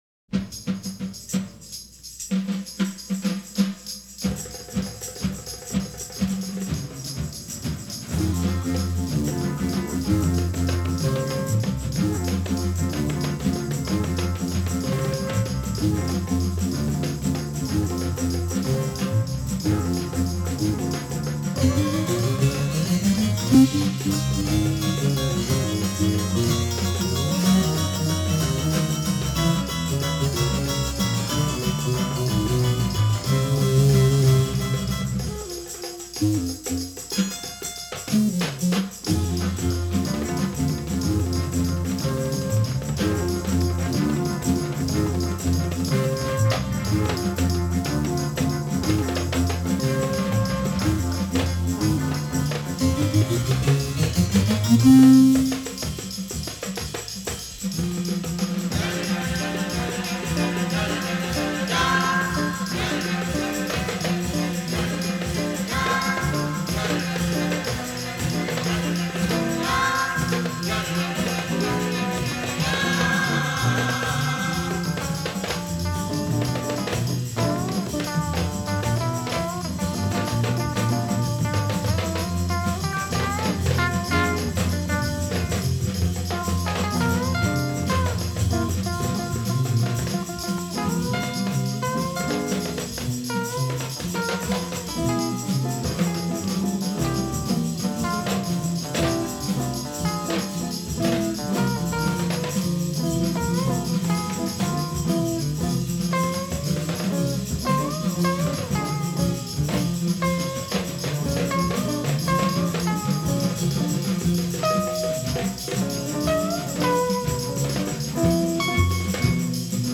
Жанр: Rock-n-Blues